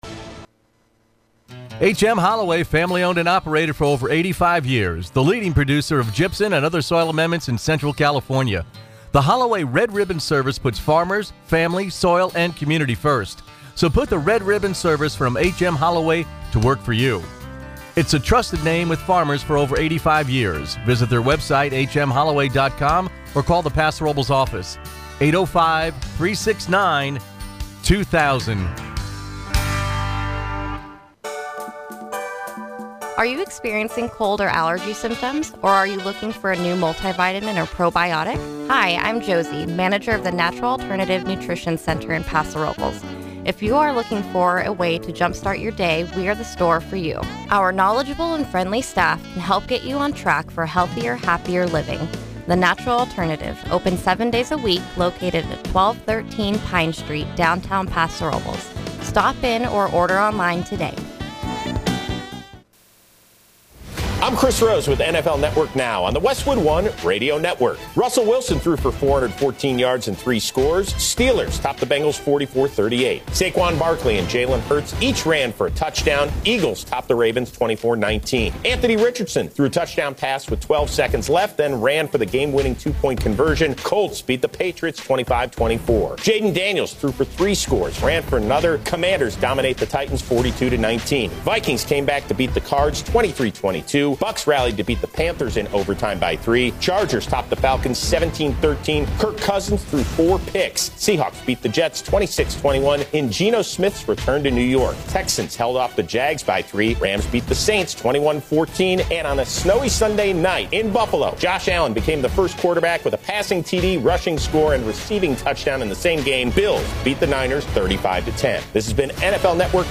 The Morning Exchange; North County’s local news show airs 6 a.m. to 9 a.m. every weekday.
Weather every hour around the clock. Call in and let your voice be heard on a number of topics.